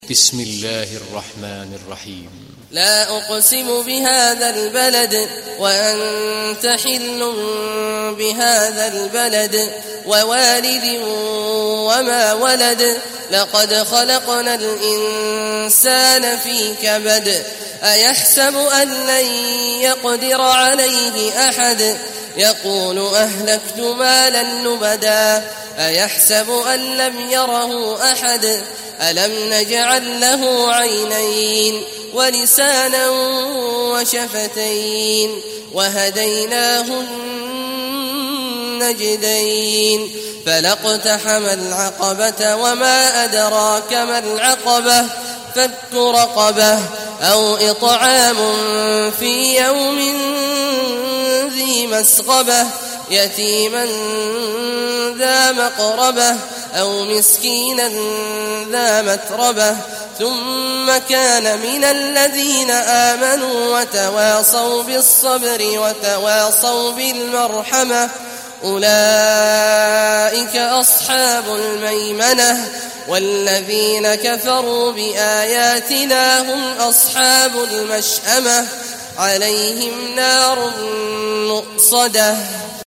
Beled Suresi İndir mp3 Abdullah Awad Al Juhani Riwayat Hafs an Asim, Kurani indirin ve mp3 tam doğrudan bağlantılar dinle